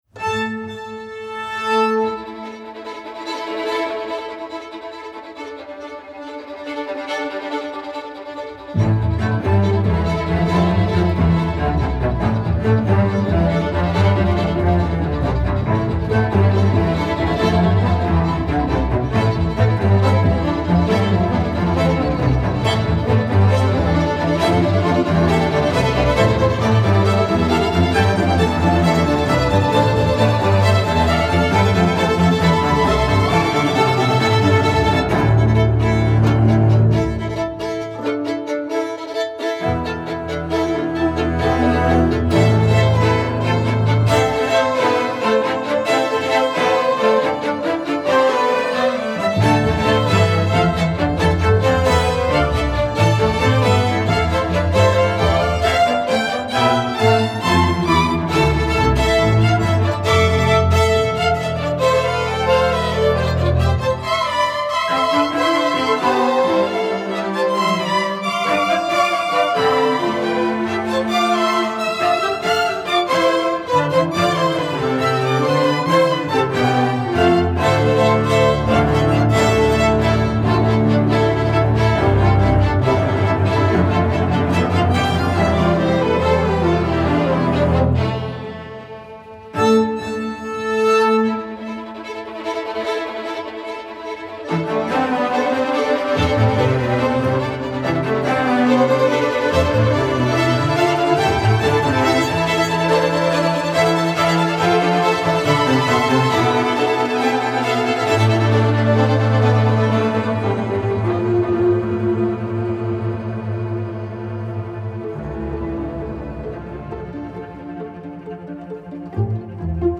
Voicing: Strg Orch